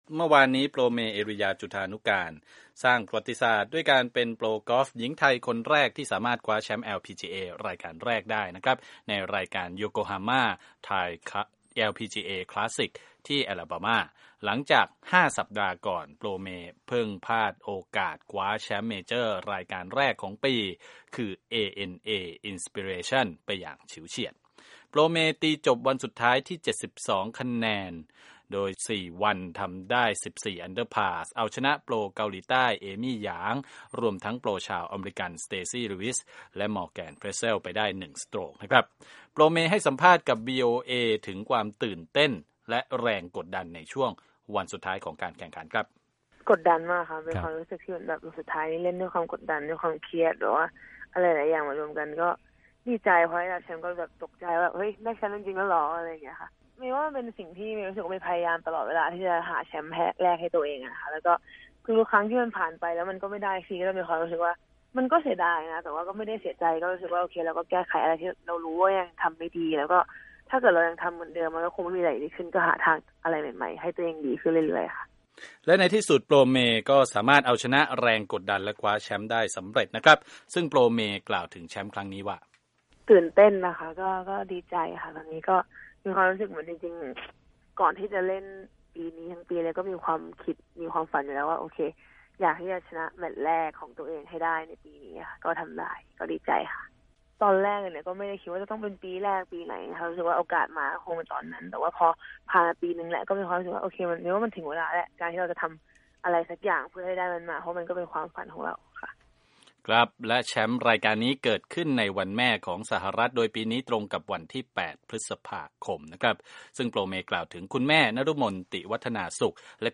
สัมภาษณ์โปรเม - เอรียา จุฑานุกาล